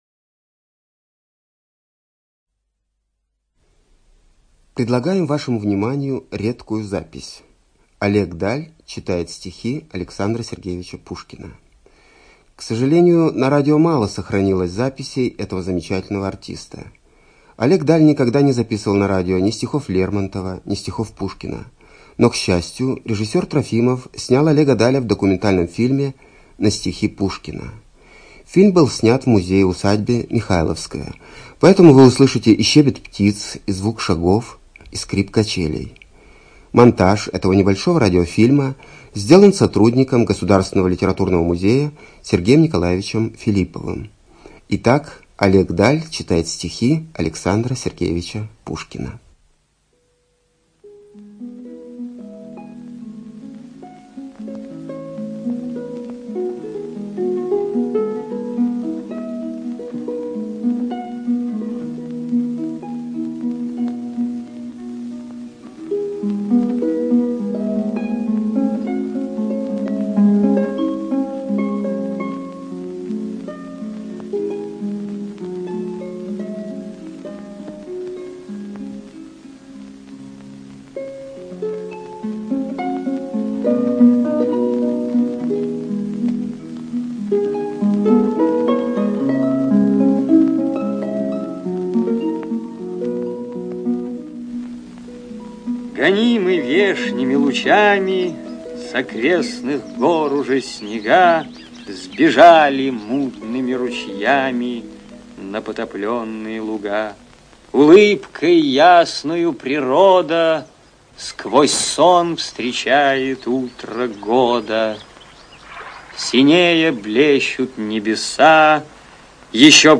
ЧитаетДаль О.
ЖанрПоэзия